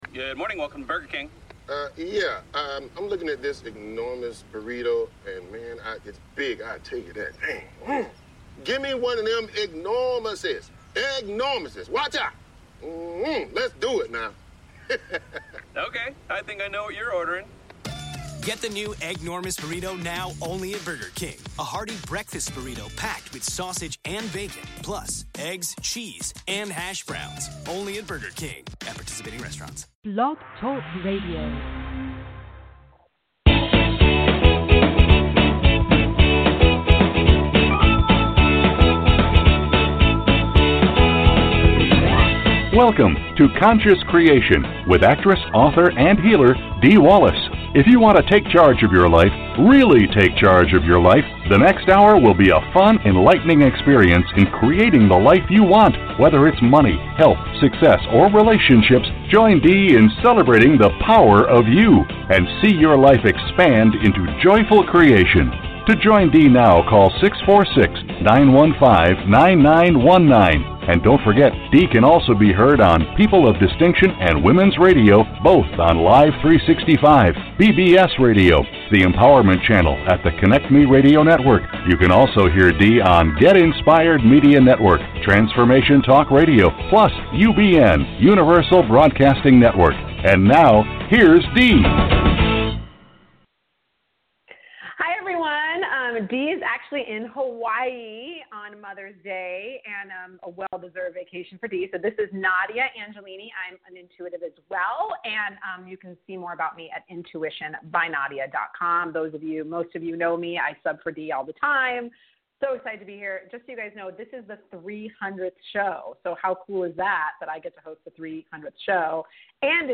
Talk Show Episode, Audio Podcast, Conscious Creation and with Dee Wallace on , show guests , about Spiritual Readings,Core Truths,Balanced Life,Energy Shifts,Spirituality,Spiritual Archaeologist,Core Issues,Spiritual Memoir,Healing Words,Consciousness, categorized as Kids & Family,Paranormal,Philosophy,Psychology,Personal Development,Spiritual,Access Consciousness,Medium & Channeling,Psychic & Intuitive